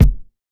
Kicks
DJP_KICK_ (82).wav